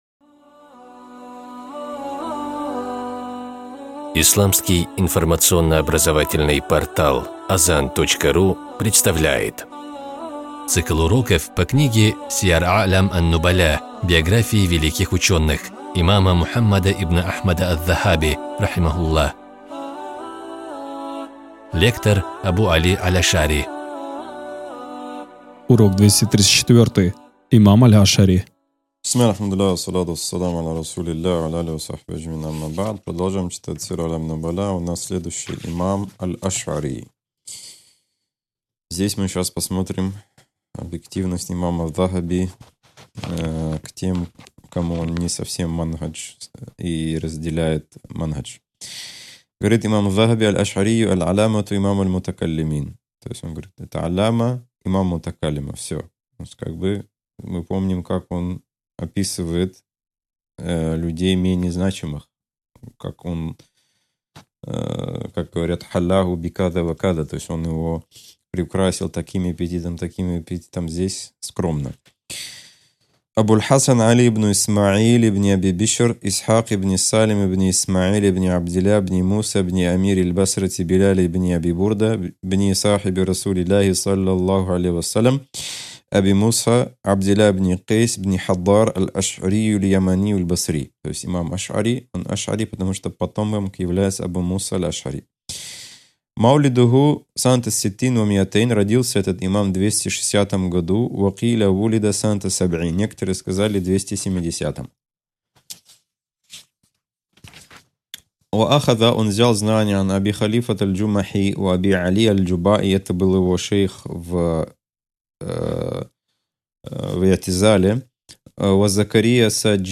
Цикл уроков по книге великого имама Аз-Захаби «Сияр а’лям ан-нубаля». Биографии исламских ученых для мусульман — не просто история, но и пример для подражания верующих, средство для улучшения их нрава.